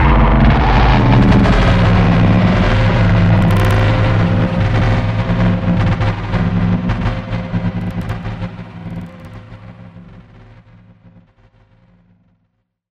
Звук падения мощности, выключения питания (2)
Библиотека Звуков - Звуки и звуковые эффекты - Видео и кино эффекты